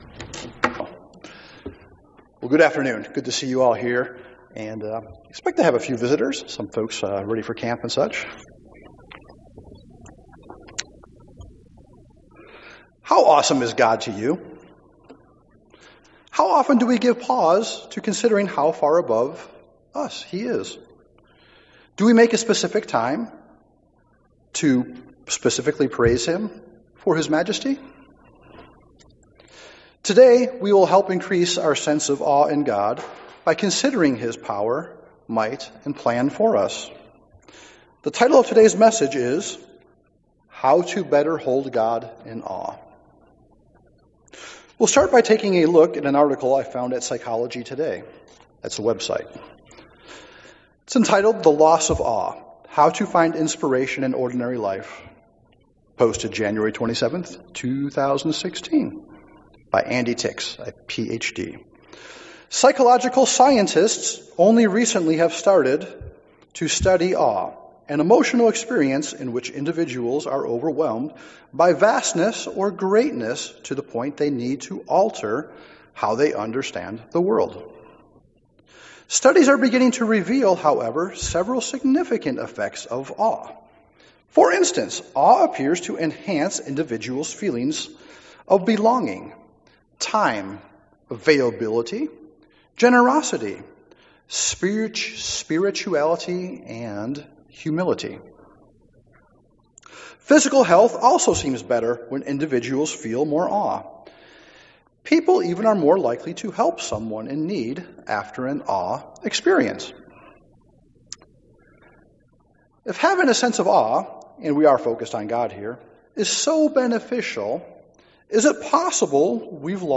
This sermon walks through many awe-inspiring scriptures showing how far above us God really is. Meanwhile, a number of passages feature how far below Him we really are.